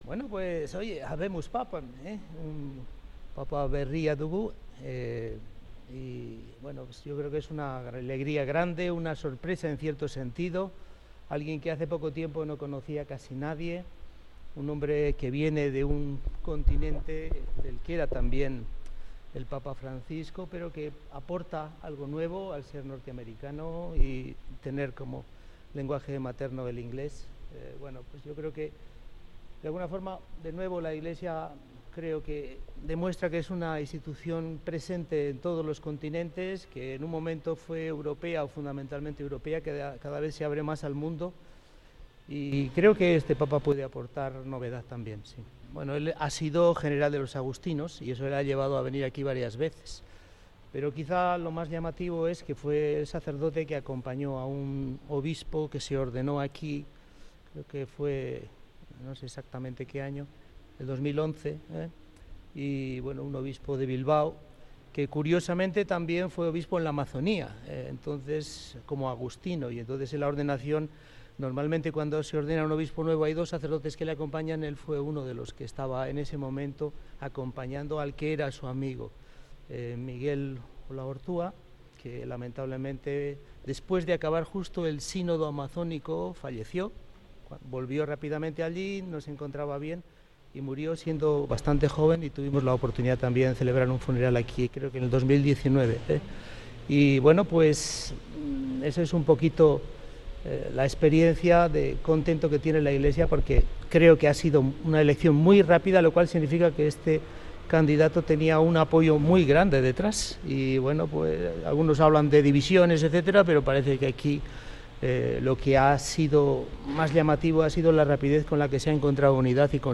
El Obispo de Bilbao valora el nombramiento de Robert Francis Prevost